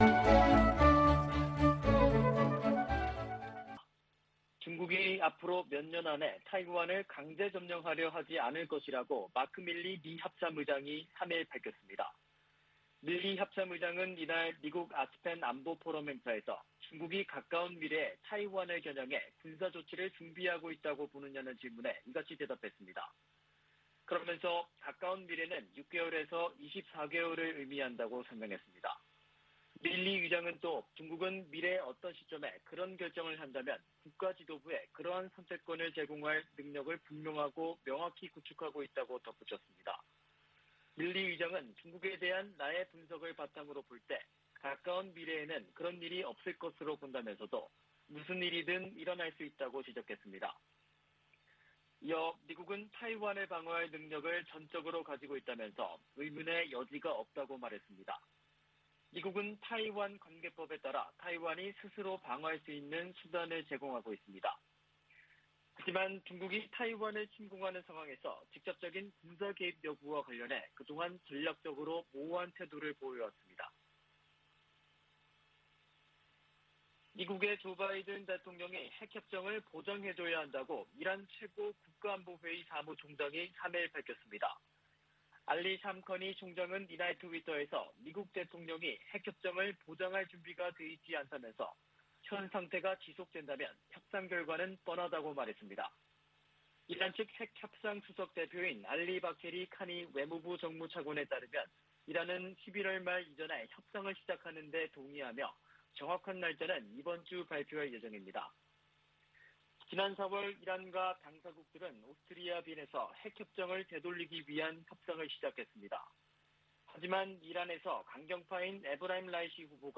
VOA 한국어 아침 뉴스 프로그램 '워싱턴 뉴스 광장' 2021년 11월 4일 방송입니다. 미 연방법원이 중국 기업과 관계자들의 대북제재 위반 자금에 대해 몰수 판결을 내렸습니다. 중국과 러시아가 유엔 안보리에 다시 대북제재 완화 결의안을 제출한 것은 미국과 한국의 틈을 벌리기 위한 것이라고 미국 전문가들이 분석했습니다.